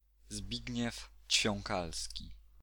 Zbigniew Ćwiąkalski (IPA: [ˈzbiɡɲɛf t͡ɕfjɔŋˈkalskʲi]